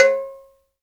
Index of /90_sSampleCDs/Roland LCDP14 Africa VOL-2/PRC_Afro Toys/PRC_Afro Metals